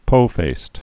(pōfāst)